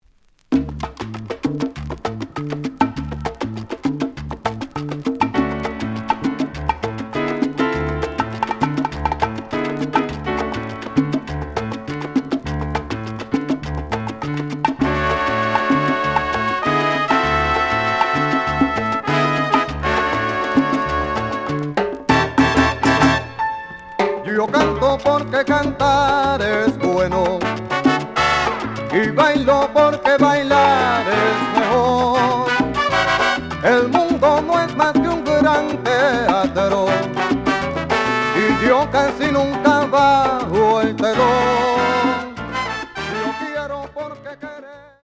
The audio sample is recorded from the actual item.
●Genre: Latin